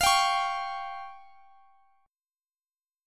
Listen to Fsus2#5 strummed